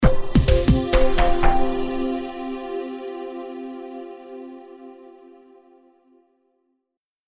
جلوه های صوتی
دانلود صدای تلویزیون 4 از ساعد نیوز با لینک مستقیم و کیفیت بالا